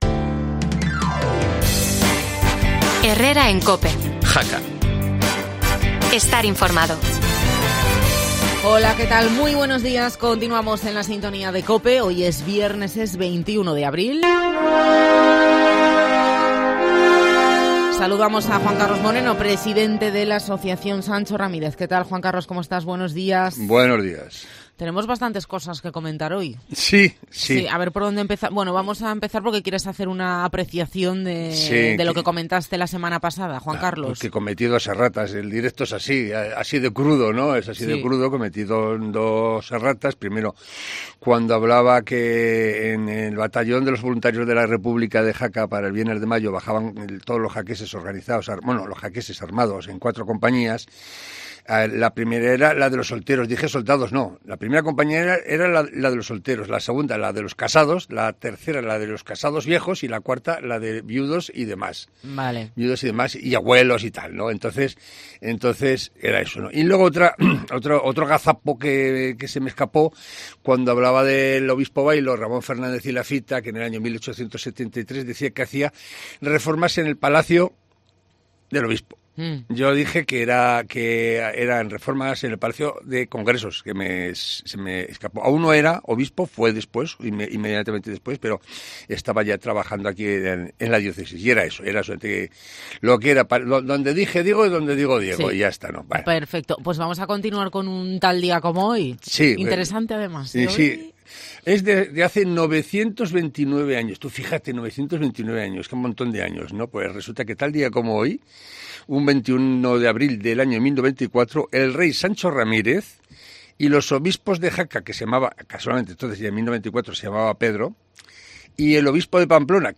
Además, nos ofrece un "TAL DÍA COMO HOY" que te va a encantar No te pierdas la entrevista en COPE